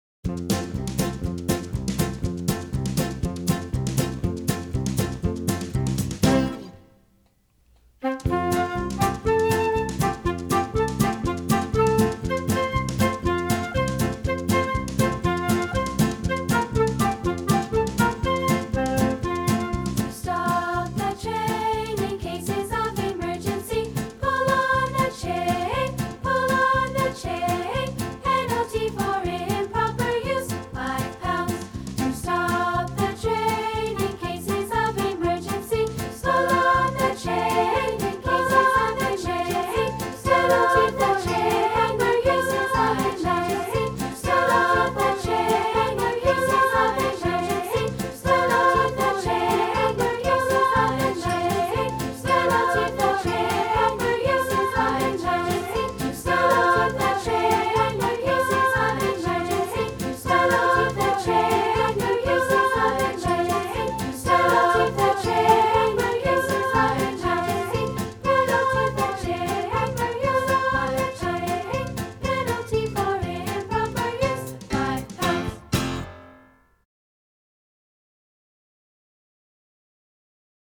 And here is a round we are learning for Primary Days of Music – To Stop the Train